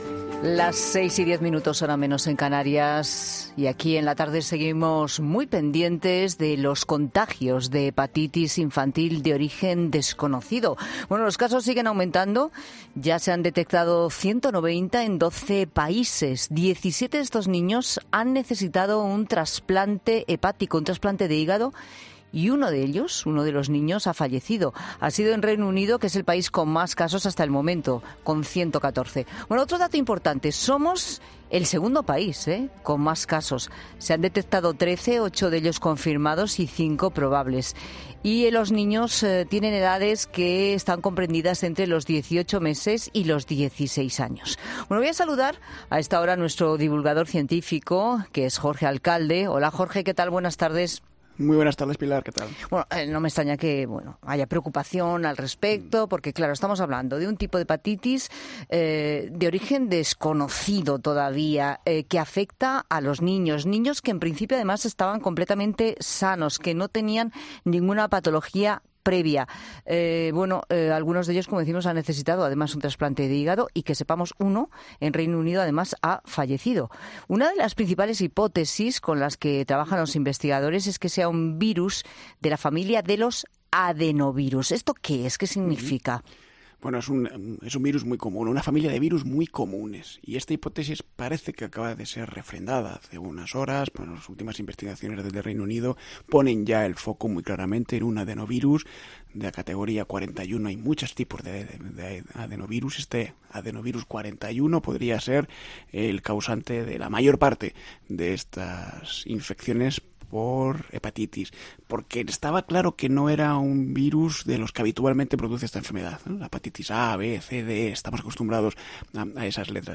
El divulgador científico y colaborador de 'La Tarde', Jorge Alcalde, ha explicado que se trata de una familia de virus "muy común"